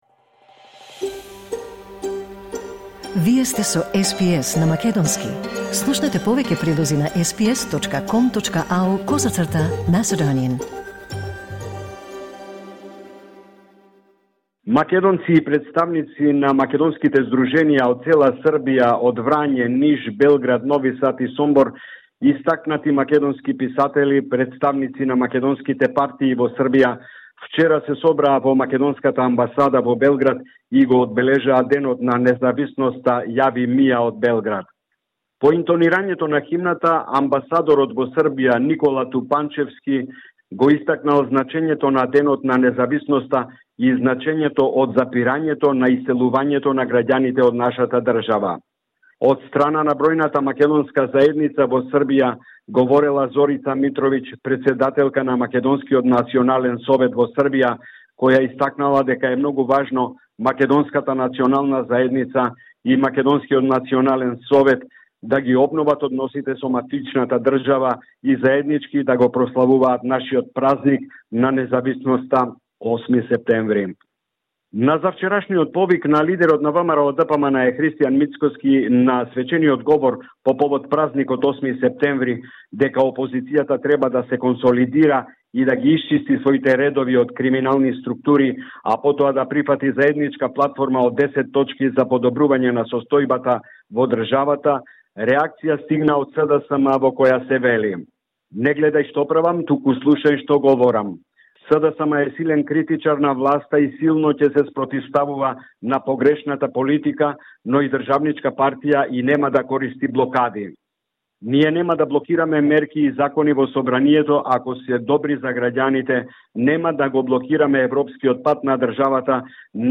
Извештај од Македонија 10 септември 2024